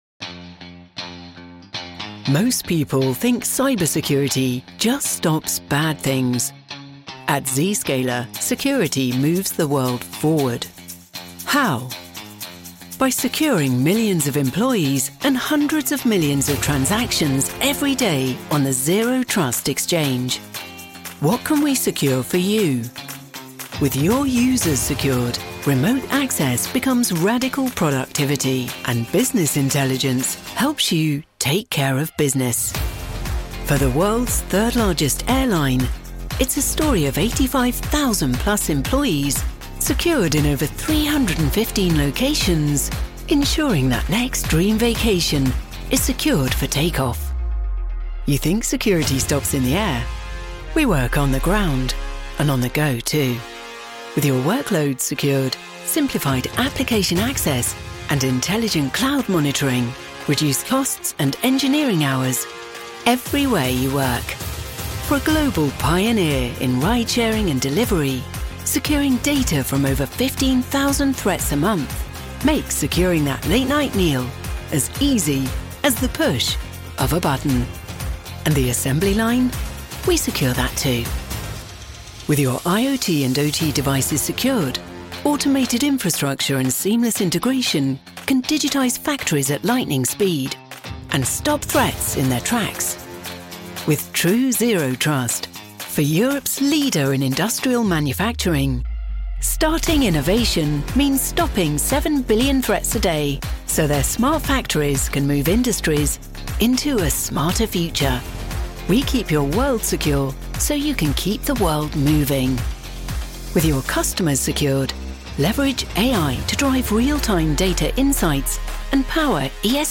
English (British)
Commercial, Warm, Versatile, Friendly, Corporate
Corporate